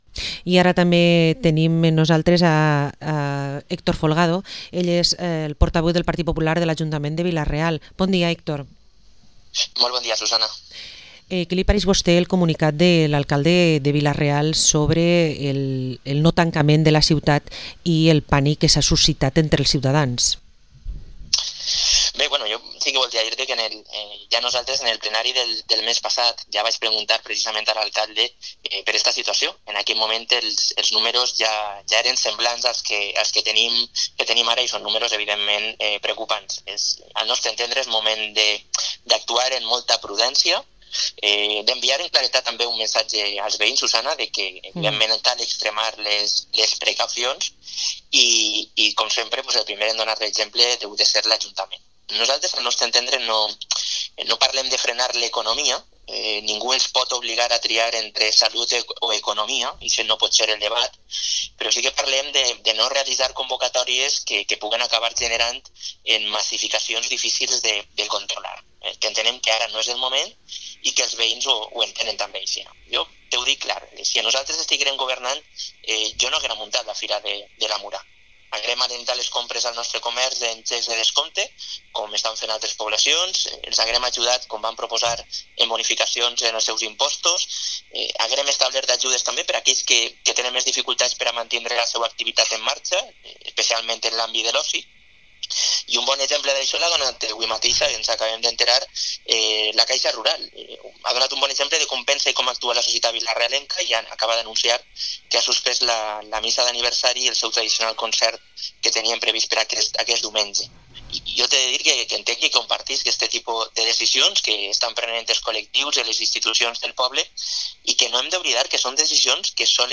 Entrevista a Héctor Folgado, portavoz del PP de Vila-real